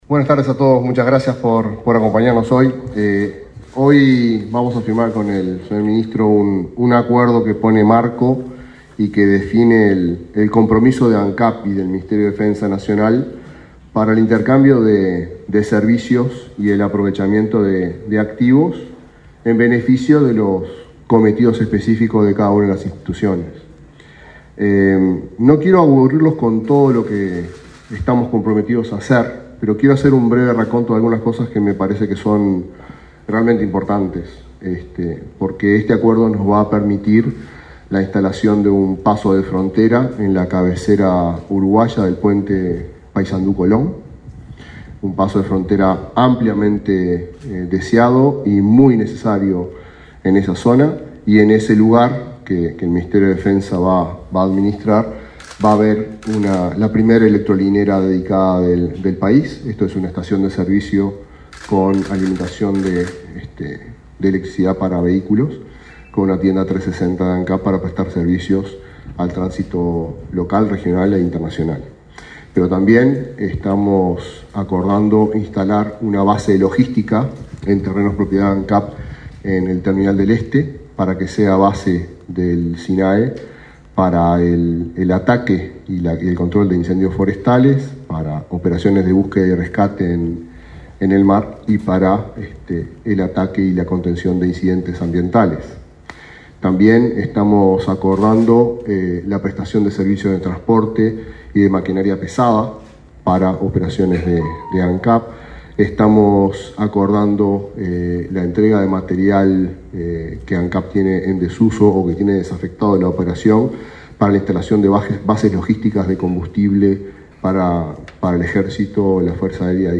Conferencia de prensa por acuerdo entre el MDN y Ancap
Participaron del evento el ministro Javier García y el presidente de Ancap, Alejandro Stipanicic.